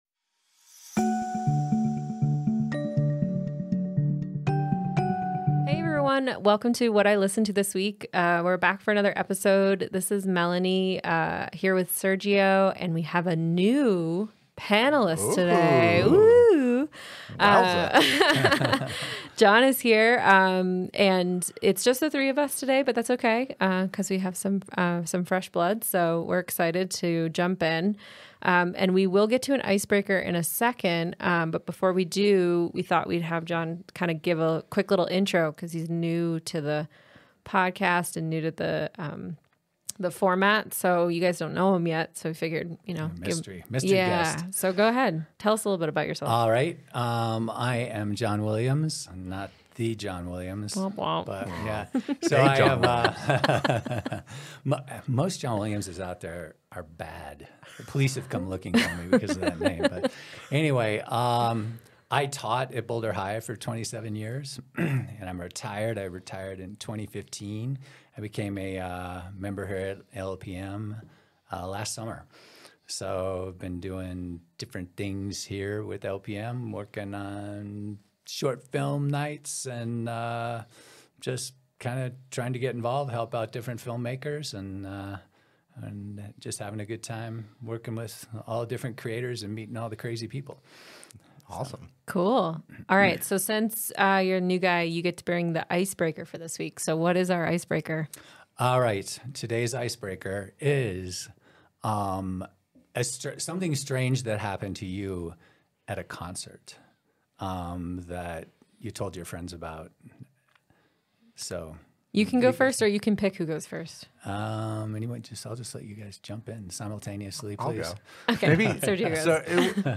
In this episode, our panelists dissect each track, offering insightful discussions and passionate reflections that will enhance your love for music.